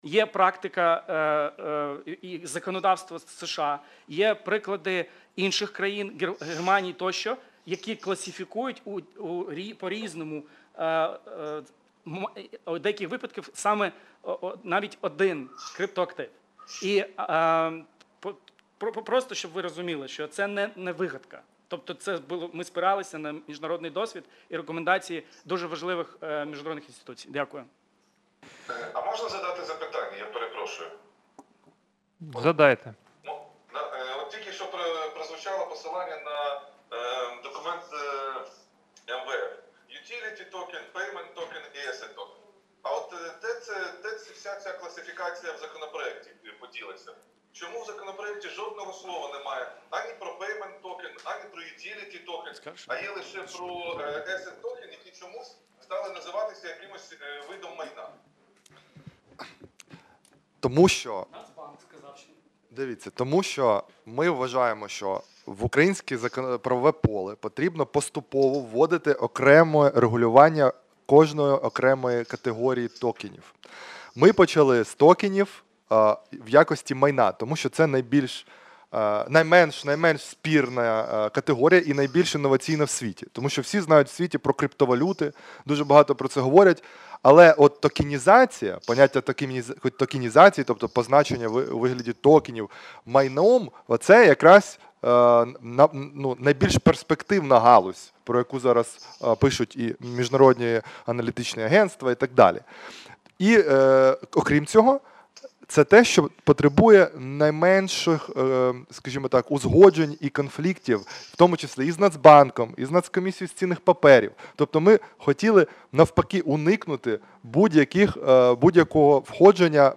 Аудіозапис засідання Комітету від 15.07.2020